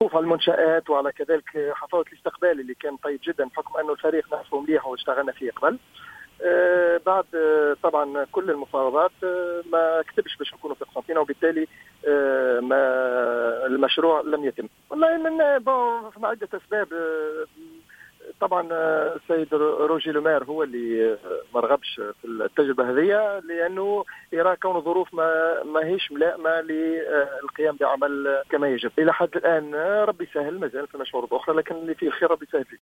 في تصريح لراديو جوهرة أف أم أنه عاد إلى تونس مباشرة بعد أن فشل الإتفاق و أنه ليس بحوزته عرض في الوقت الحالي .